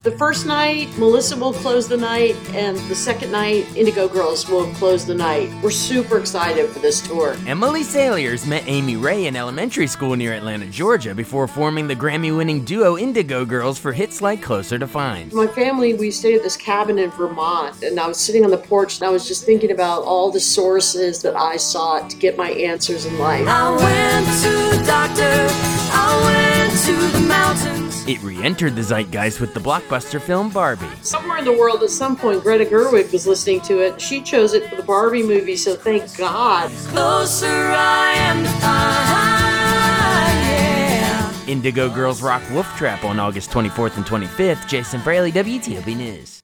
wtop - washington, d.c. (wolftrap preview) (captured from webcast)